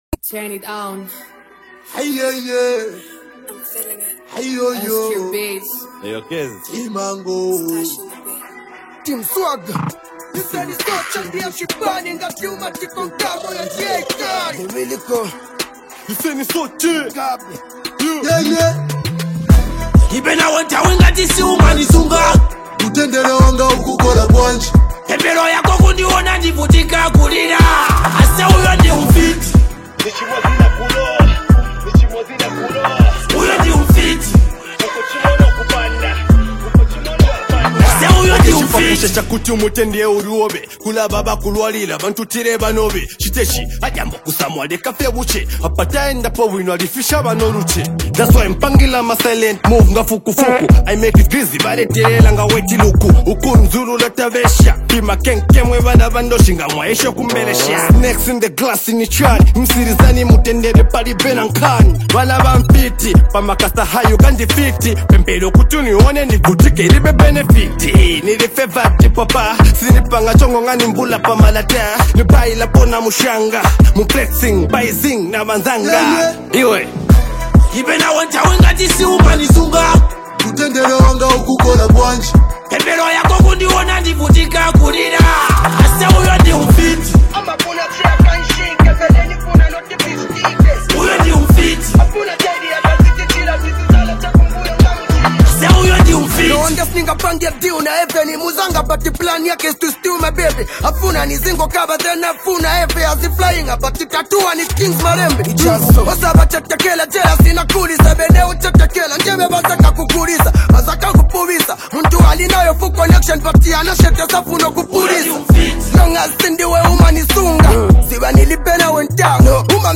merging two distinct styles into one harmonious hit.
Genre: Zambian Music